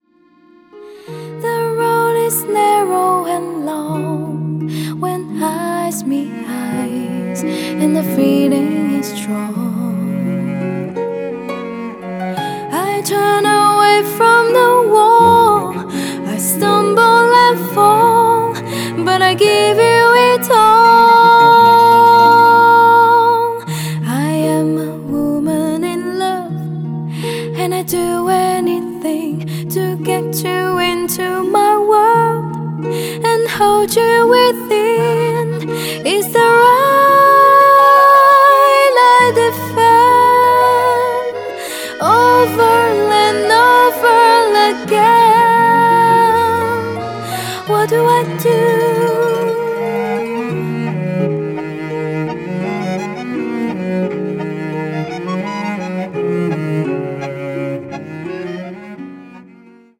vocal
Eternal Singing Endless - DSD Mastering